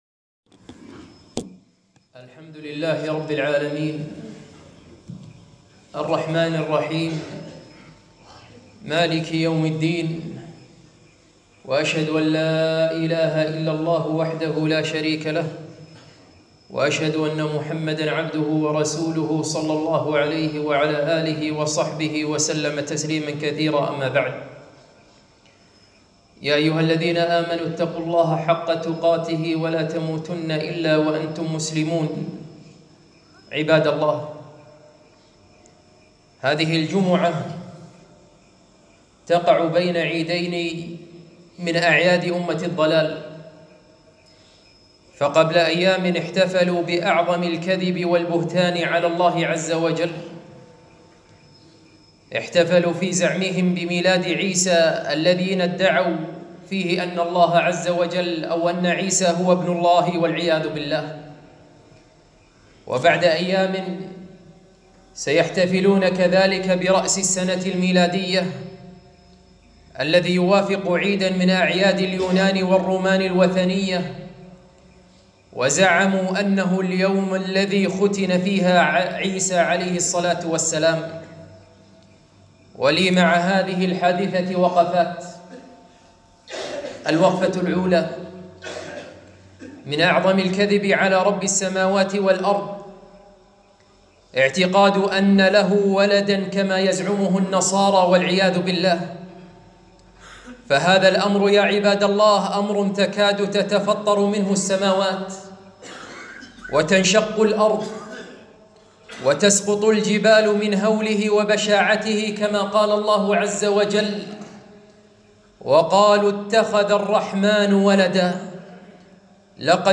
خطبة - اثنتا عشرة وقفة مع السنة الميلادية